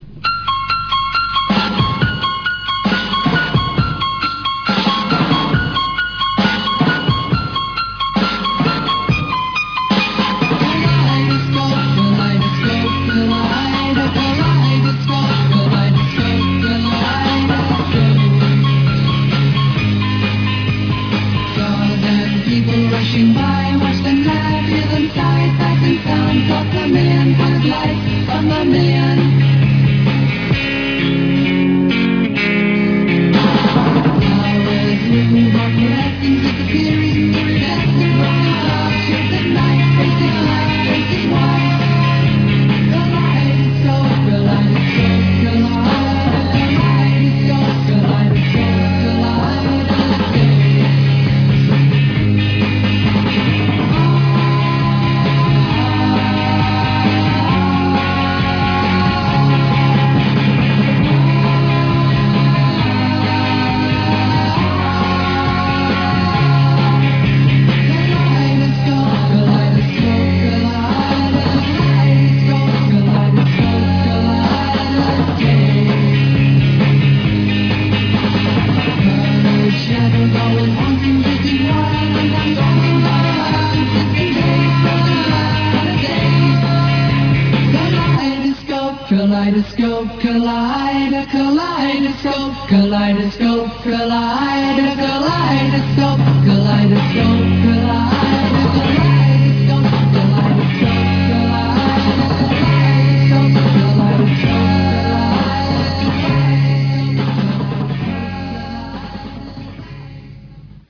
The airchecks feature the station, in its heyday, signing on at 1000 hrs on Sunday December 29th 1973 and two programming extracts from around the same period.